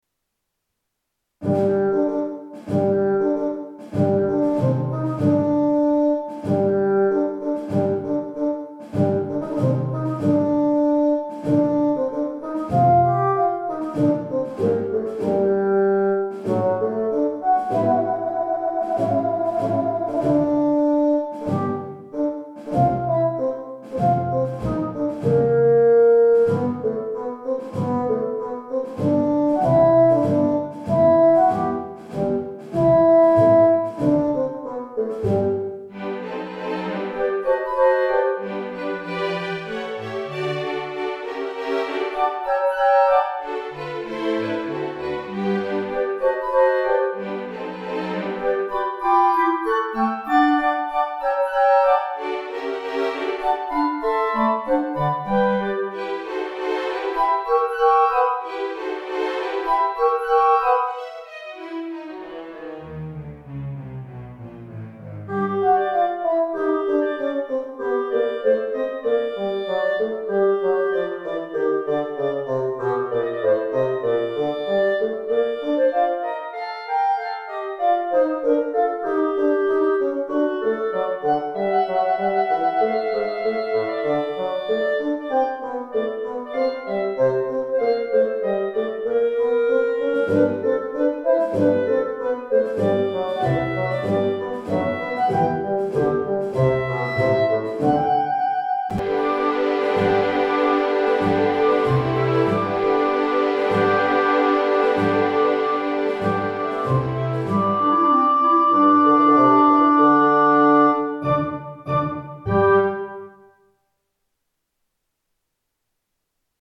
個人的にはかなり気に入っていて、音質は今までの中ではものすごくいいと思います。
アルカラの踊りはファゴットのソロが印象的です。